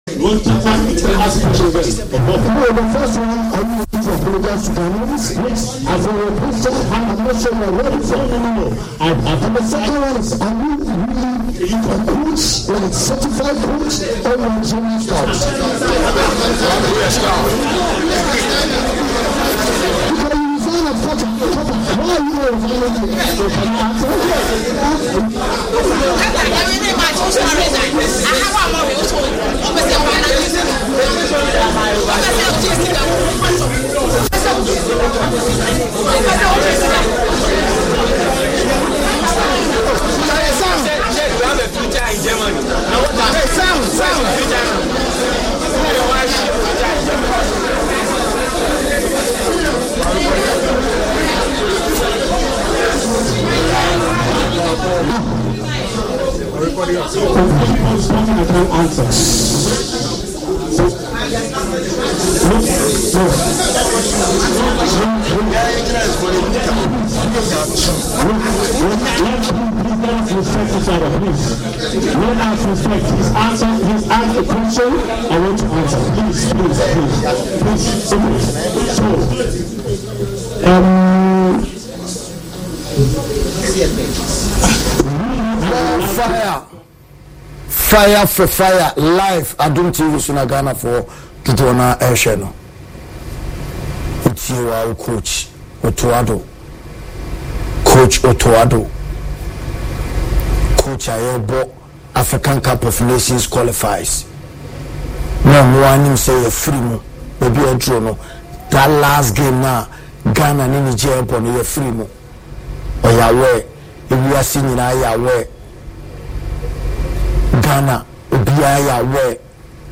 Sports monologue show